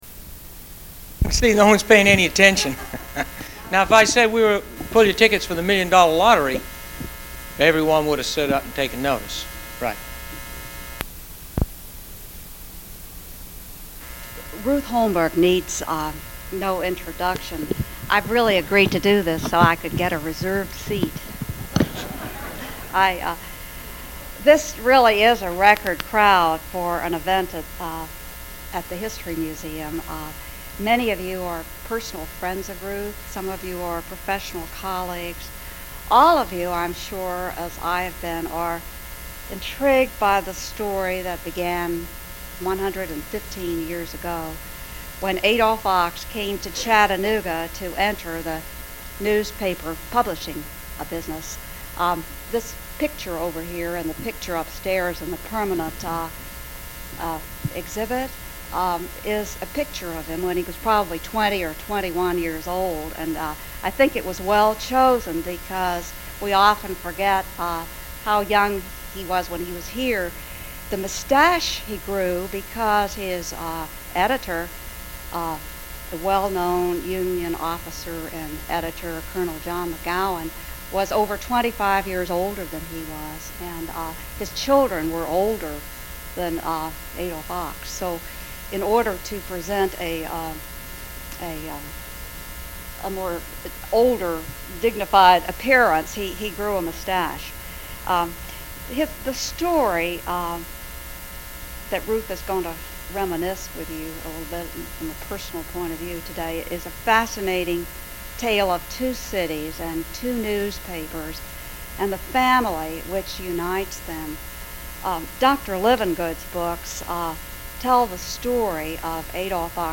"Fireside Chat" presentation